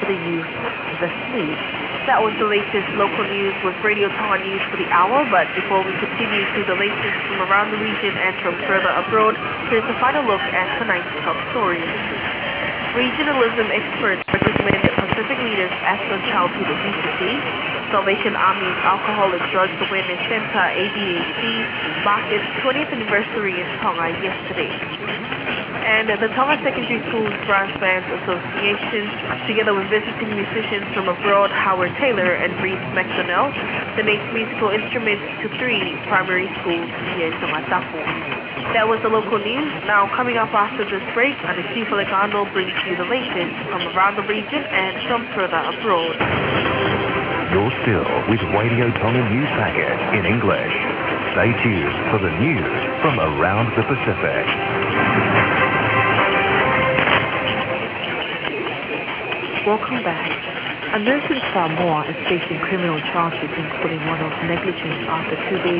[IRCA] Tonga English News
Now that Tonga appears to have extended their schedule on 1017kHz weâre able to hear things we couldnât before.
One is English news at sometime before 1245utc:
This is from Grayland, WA on August 9.